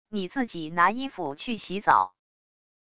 你自己拿衣服去洗澡wav音频生成系统WAV Audio Player